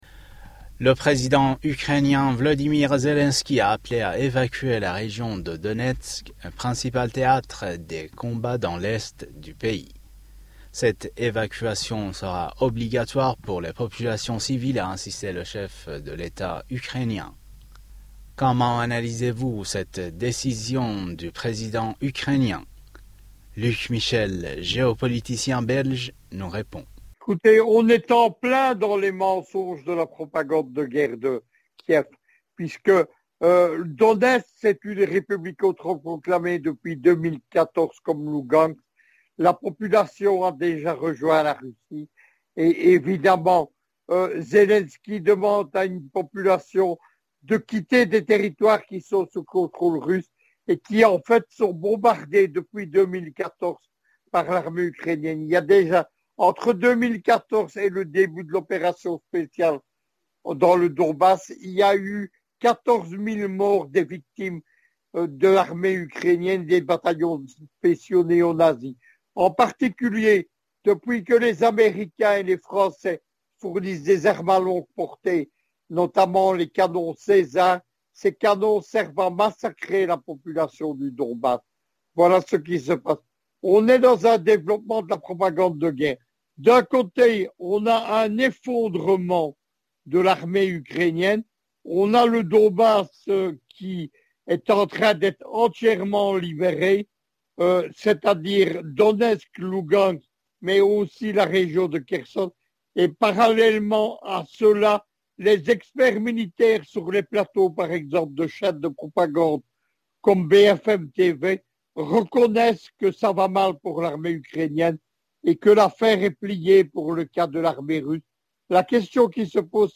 géopoliticien belge nous répond.